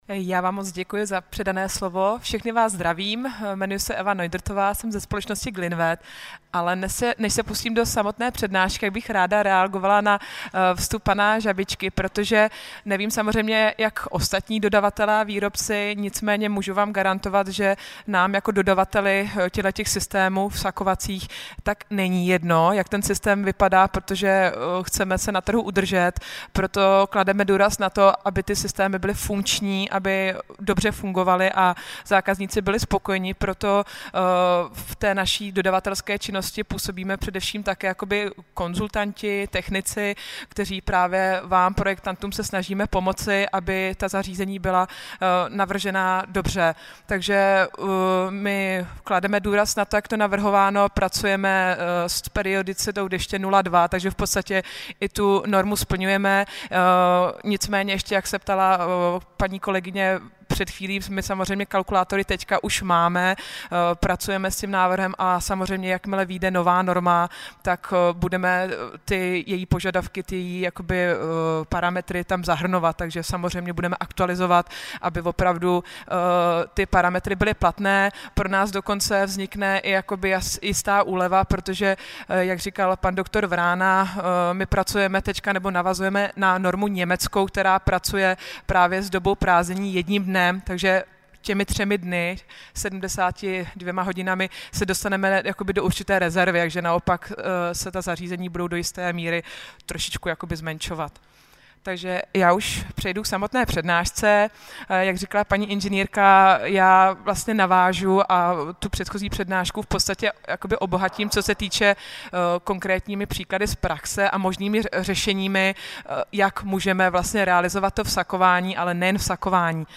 PRVN� BLOK P�EDN��EK TEPL� VODA
Ka�doro�n� odborn� semin�� po��d� na ja�e v Praze a v Brn� sekce Zdravotn� a pr�myslov� instalace Spole�nosti pro techniku prost�ed�.